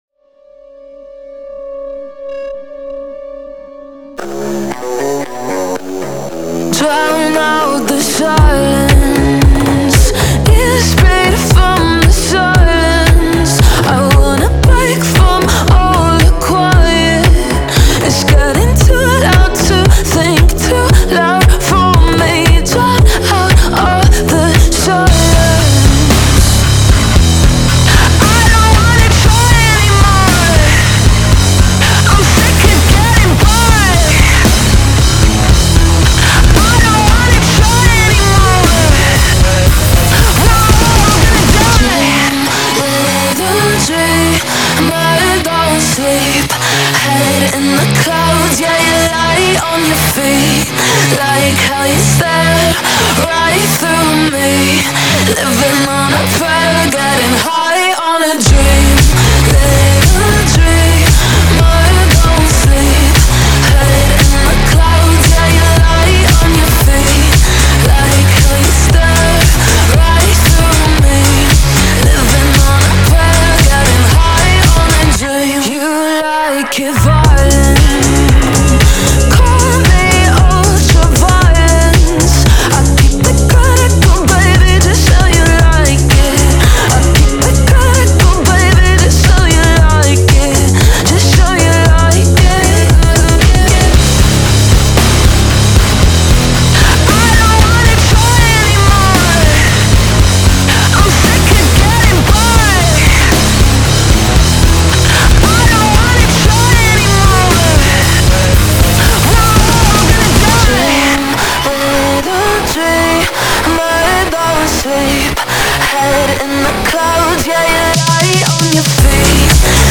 BPM115-115
Audio QualityPerfect (High Quality)
Full Length Song (not arcade length cut)